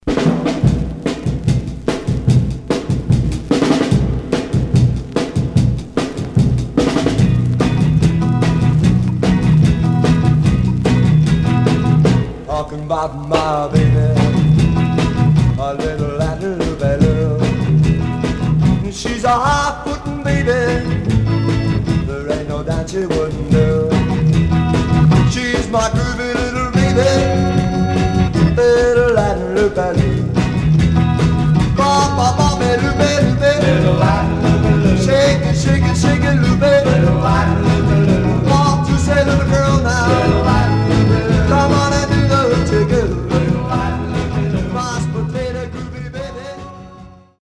Stereo/mono Mono
Garage, 60's Punk